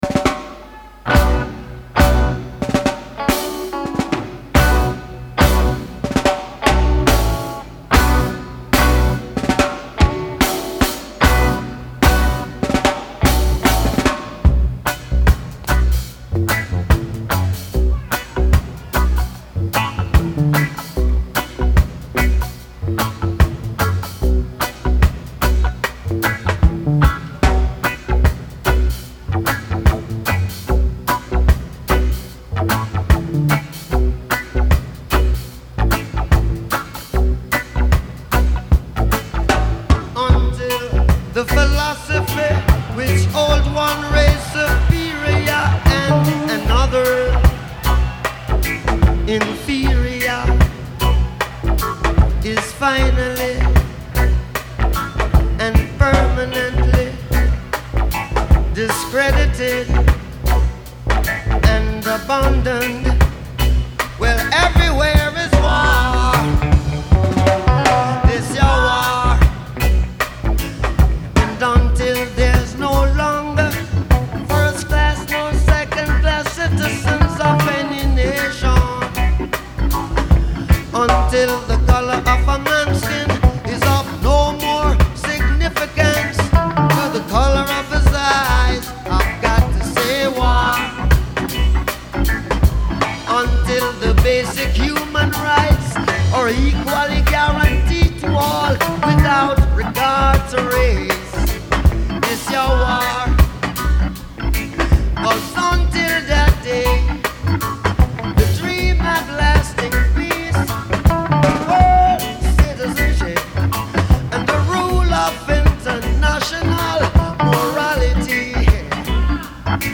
Genre : Reggae, Musiques du monde
Live At The Rainbow Theatre, London